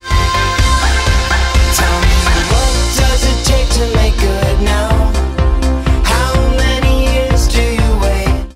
Плотный бас